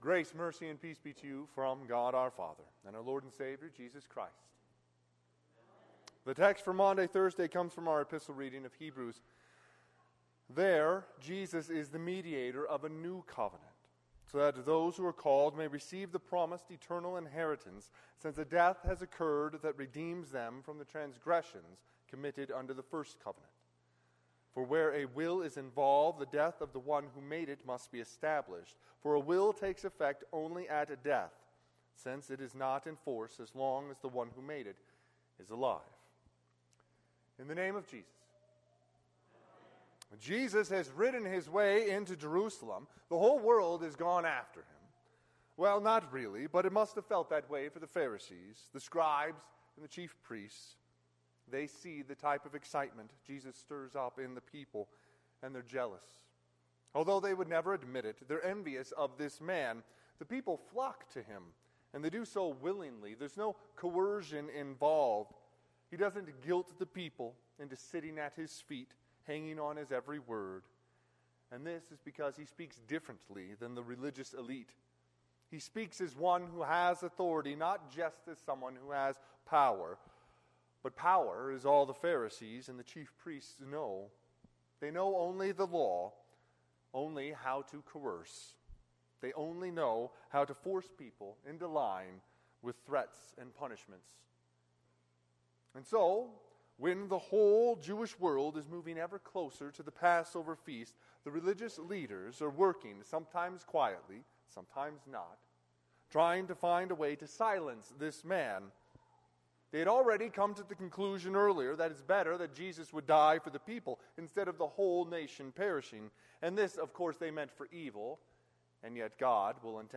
Sermon – 4/6/2023